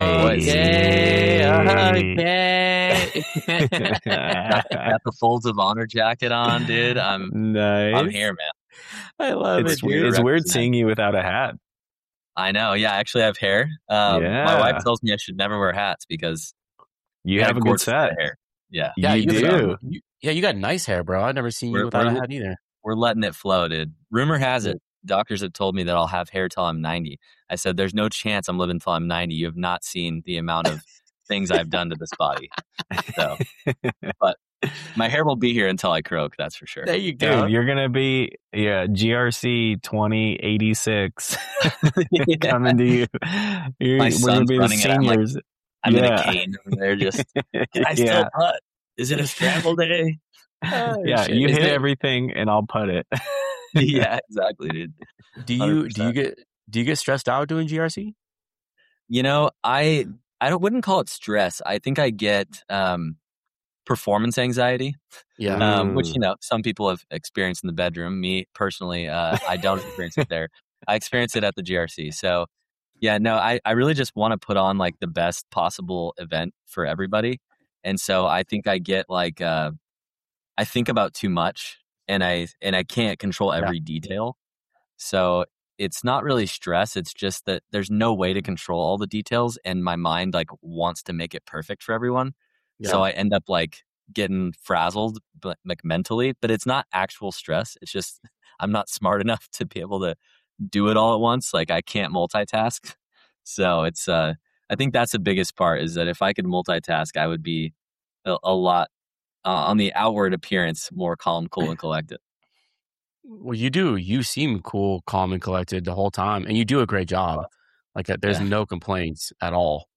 Experiences and challenges faced during the GRC (Golf Retreat Championship), touching on themes of event management, the balance between competition and enjoyment, and the mental aspects of golf. The participants share personal anecdotes, discuss the evolution of the GRC, and reflect on the importance of camaraderie and communication in enhancing the overall experience. The infamous sprinkler head incident serves as a humorous highlight, showcasing the unpredictability of golf.